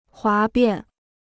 哗变 (huá biàn): mutinerie; rébellion.